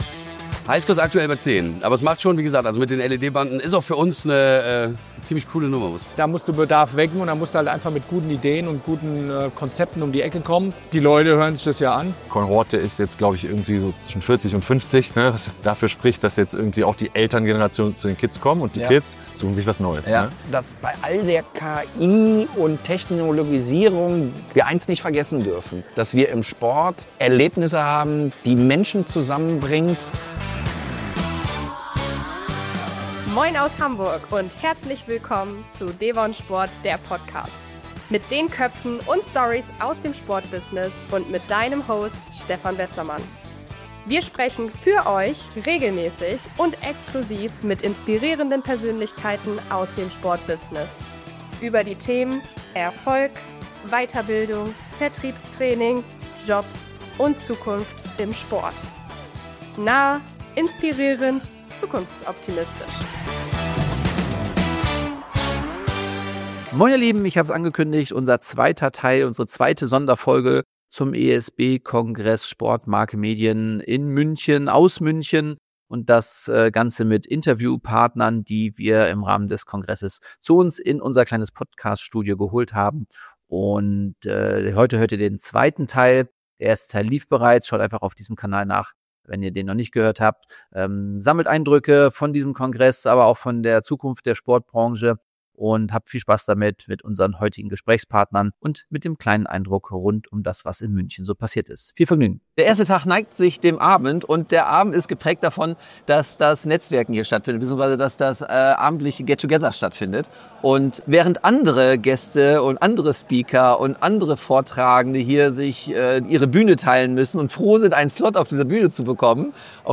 SPORT MARKE MEDIEN 2025 - LIVE!
In Teil 2 der Sonderfolge zum SPORT MARKE MEDIEN Kongress spreche ich mit 6 Gästen, die genau das beweisen: Sport lebt von echten Begegnungen – nicht trotz, sondern gerade wegen der Digitalisierung.